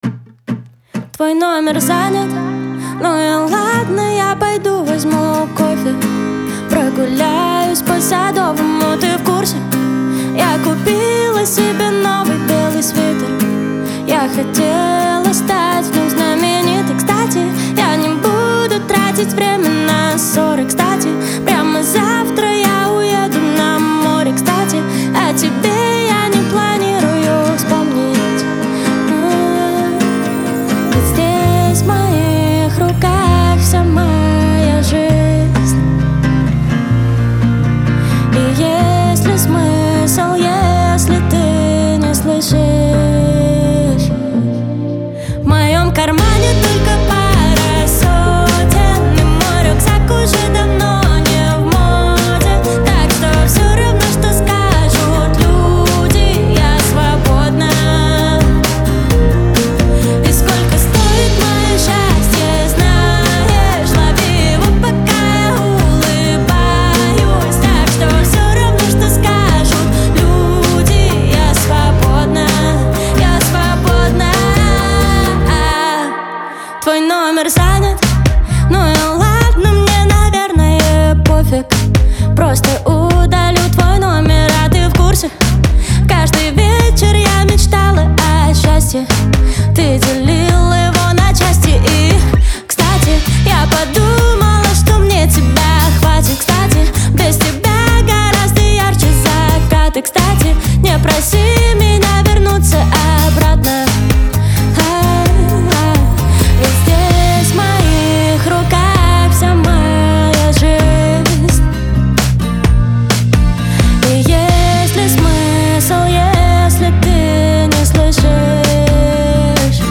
это завораживающий трек в жанре поп с элементами инди.